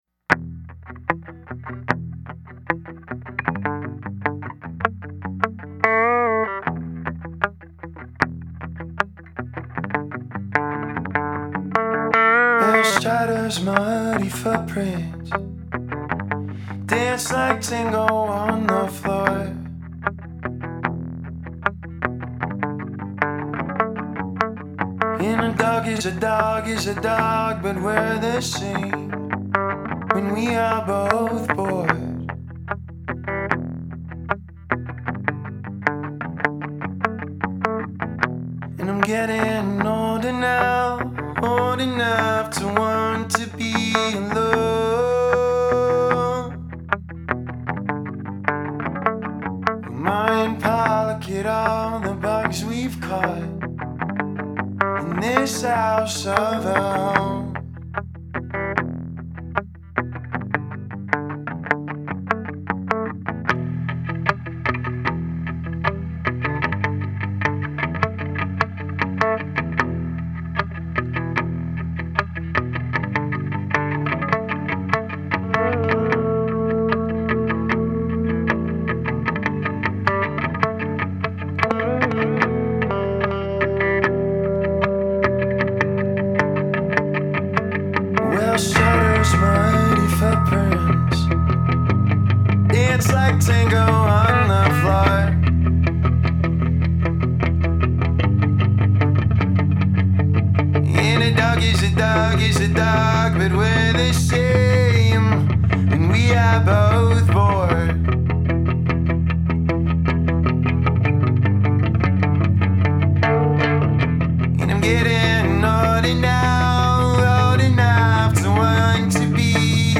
ethereal psych grunge